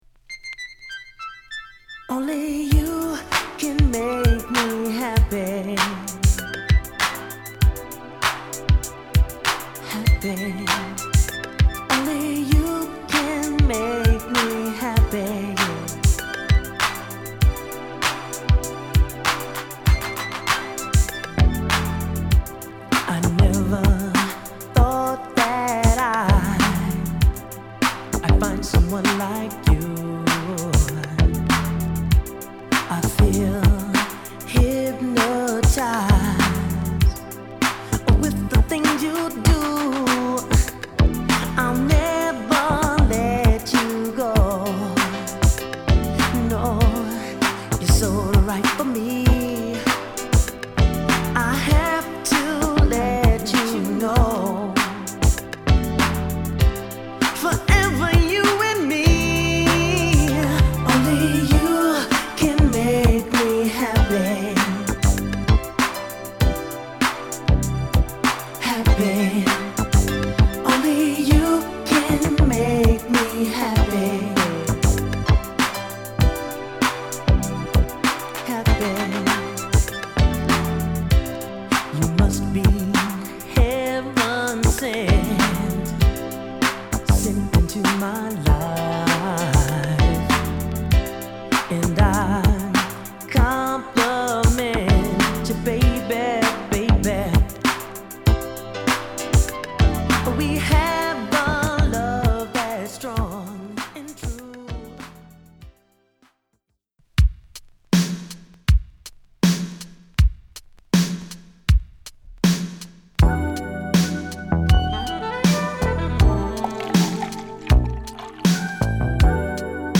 プロデュースは全て自身達によるもので、この年代らしいアーバンなメロウチューンを中心にディスコチューンまでを披露！